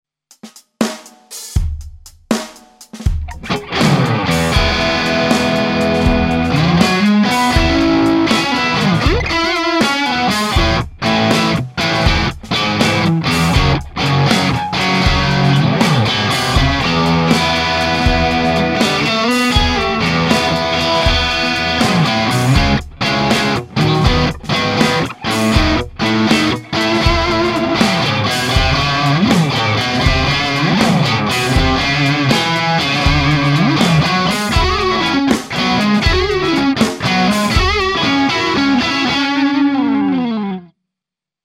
Ein Sennheiser E609 hing beide Male in der gleichen Position, ziemlich mittig vor dem Speaker.
obwohl ich den Wizard sehr schätze und mehrfach verbaut habe, muss ich doch zugeben, dass der Greenback hier präsenter, lebhafter und voller klingt (um nicht "geiler" zu sagen).